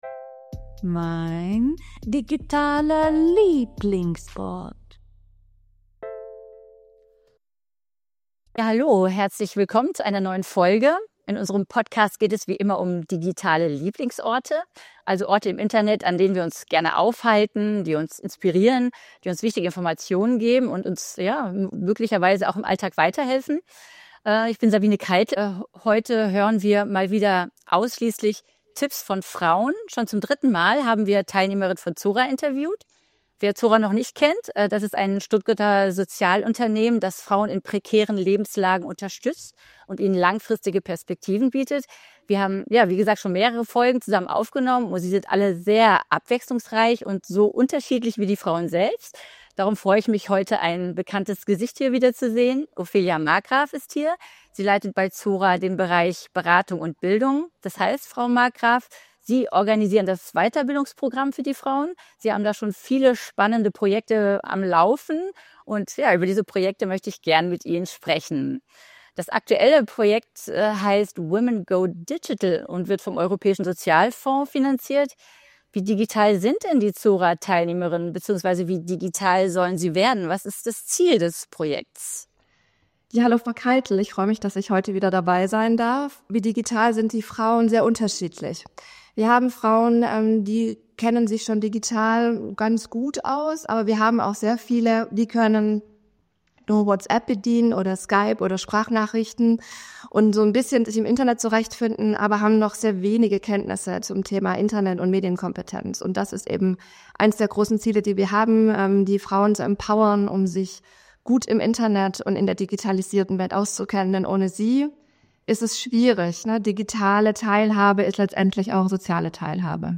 Die Teilnehmerinnen erzählen, wie diese Fähigkeiten ihnen helfen: beim Deutschlernen über YouTube, beim Finden von Unterstützungsangeboten für alleinerziehende Mütter über die Caritas oder bei der Information zu gesellschaftspolitischen Themen wie Femiziden. Die Folge gibt Einblicke in digitale Alltagspraxis und macht deutlich, wie wichtig digitale Teilhabe für Orientierung, Vernetzung und Selbstbestimmung ist.